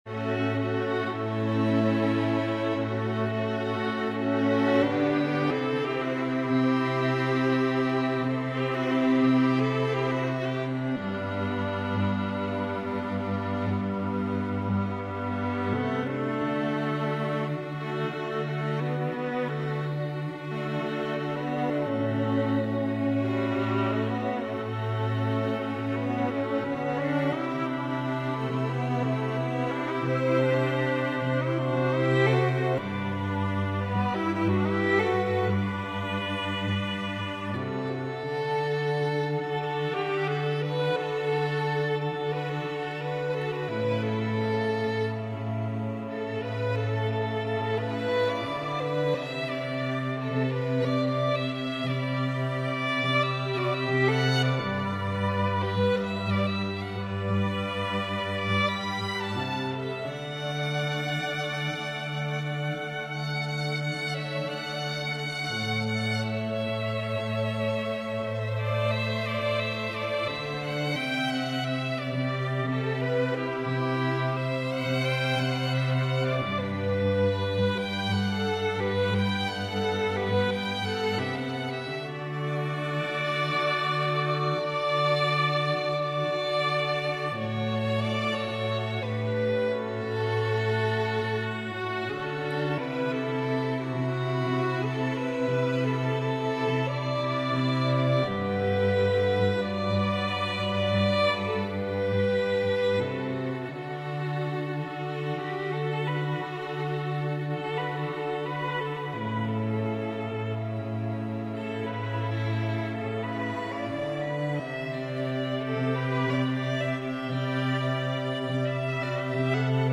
Genre: Pop/Contemporary , Wedding Arrangements Available